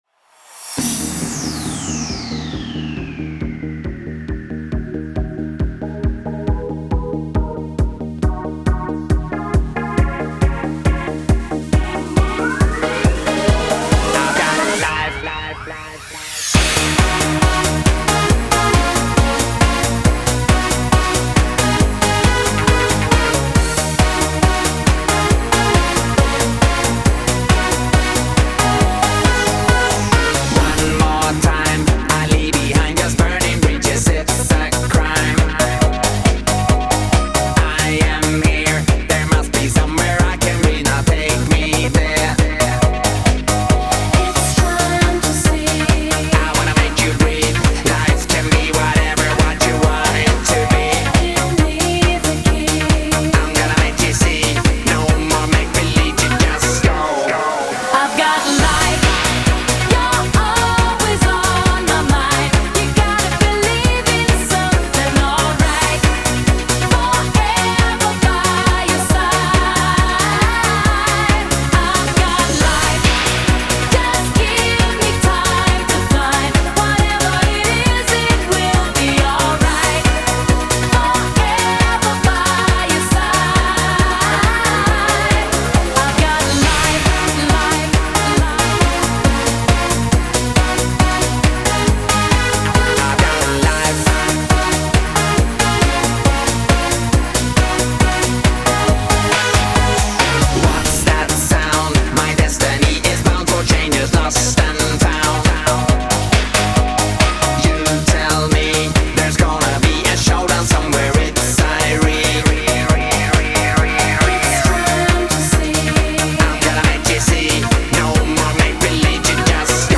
Жанр: Eurodance, Pop